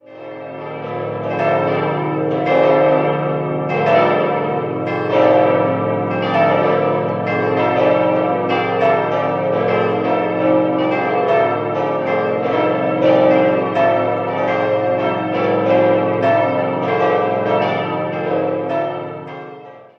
5-stimmiges Geläute: a°-c'-d'-f'-g' A lle Glocken wurden 1947 vom Bochumer Verein für Gussstahlfabrikation gegossen.